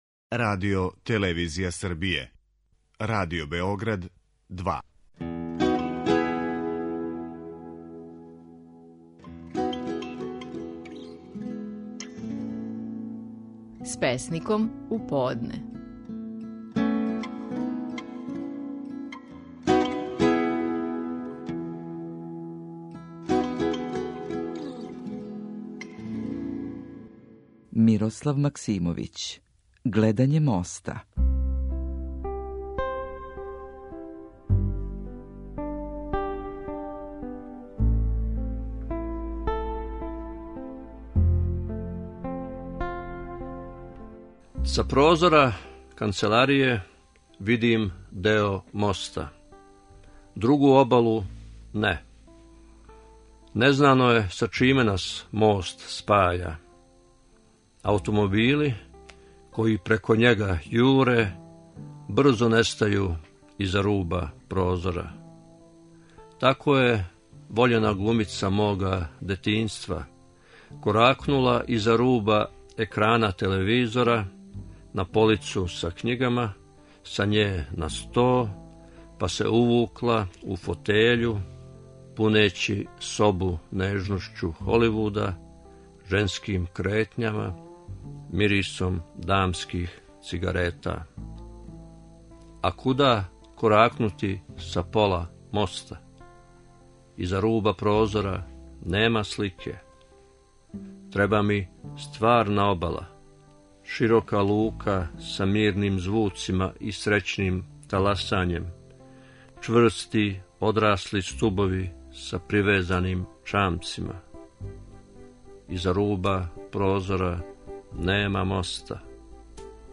Стихови наших најпознатијих песника, у интерпретацији аутора.
Мирослав Максимовић говори песму „Гледање моста".